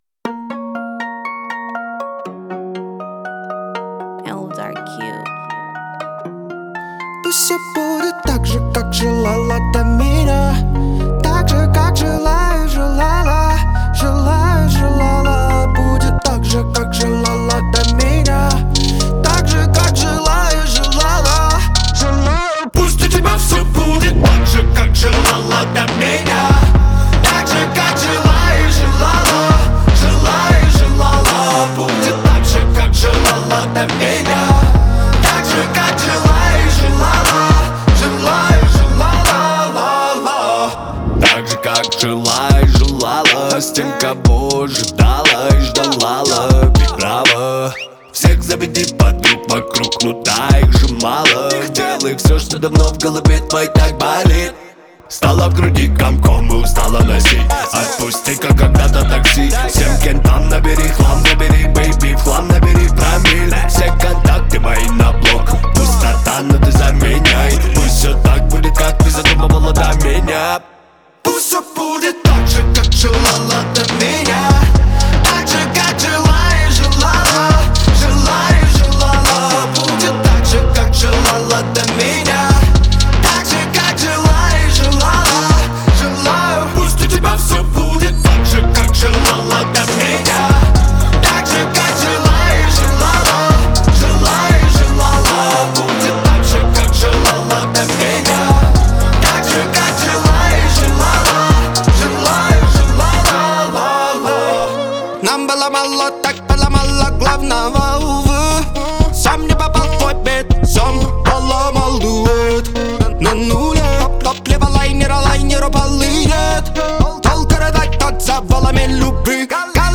это трек в жанре рэп